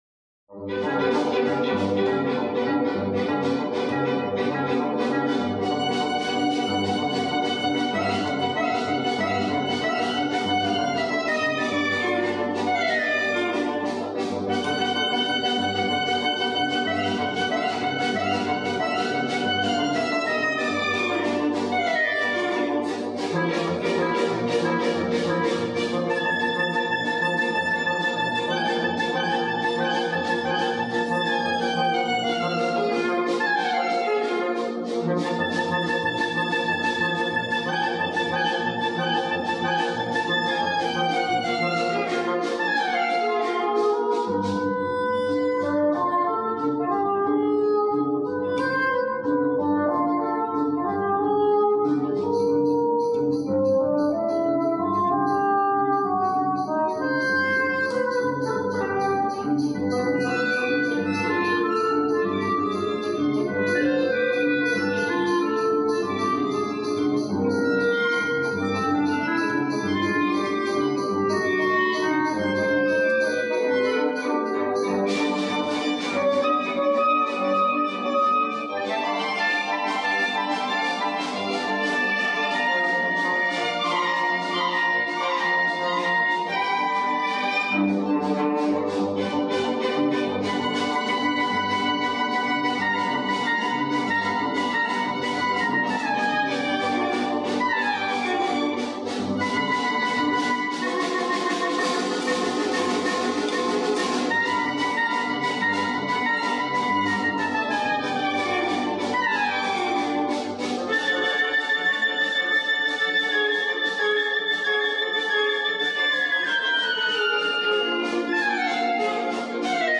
Clarinet in B flat & ABassoonPercussionViolinViolaCello